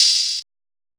BWB WAVE 5 HAT OPEN (20).wav